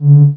1 channel
ui_selectdisc.wav